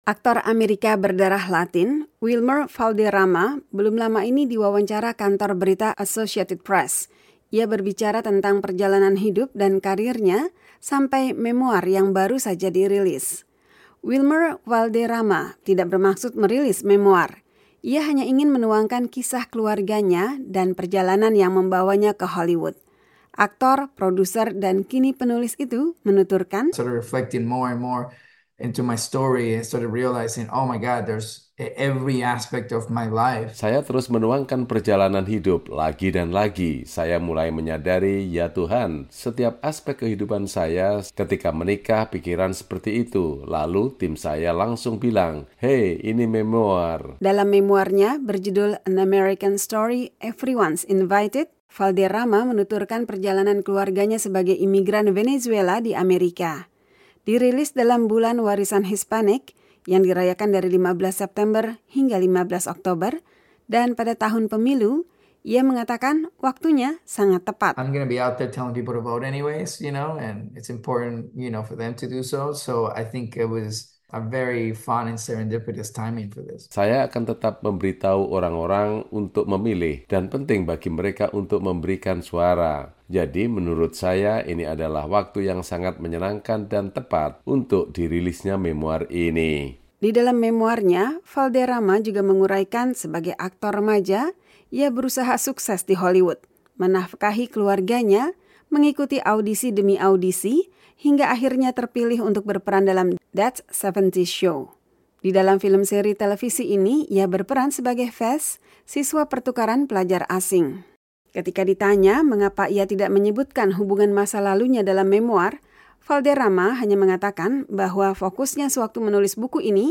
Aktor AS berdarah Latin, Wilmer Valderrama, belum lama ini diwawancara kantor berita Associated Press. Ia berbicara tentang perjalanan hidup dan karirnya sampai memoar yang baru saja dirilis.